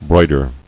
[broi·der]